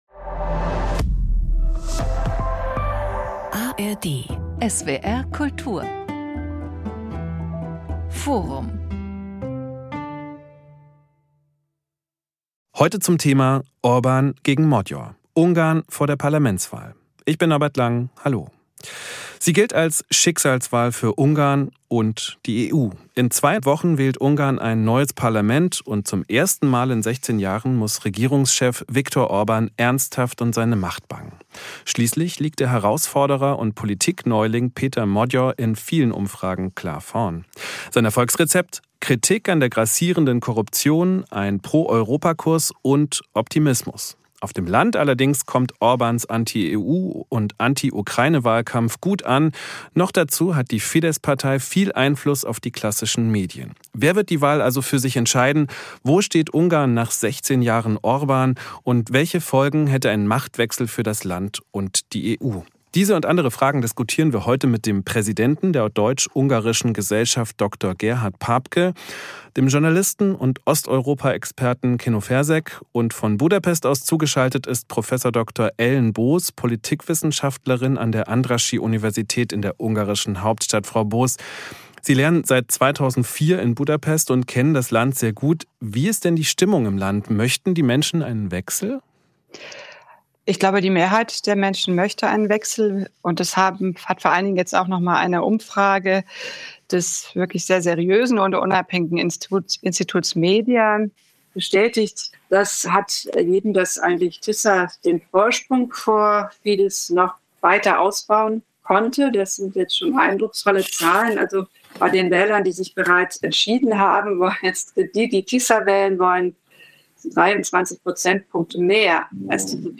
Politikwissenschaftlerin
Journalist Mehr